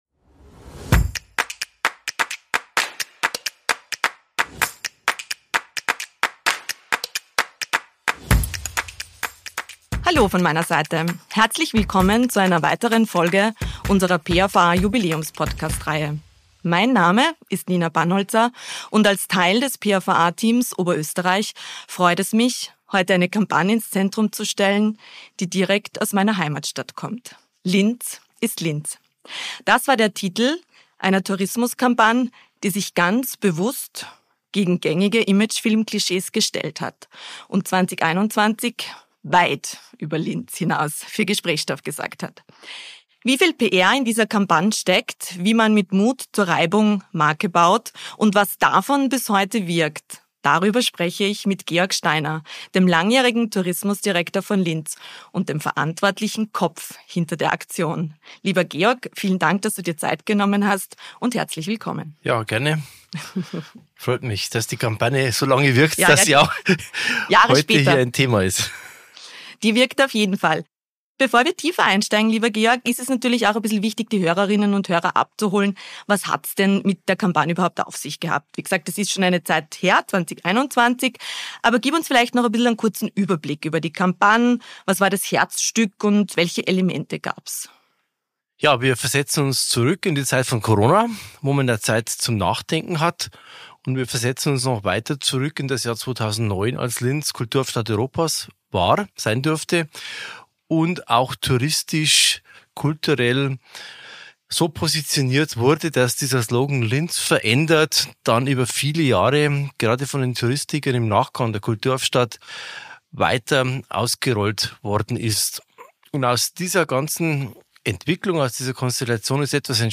Ein Gespräch über kalkulierte Reibung, überraschende Reichweite – und die Bedeutung von Haltung.